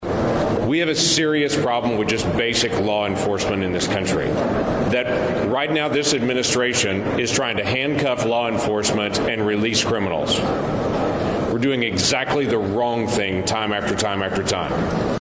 Below is a transcript of what Sen. Lankford had to say in a press conference on Wednesday.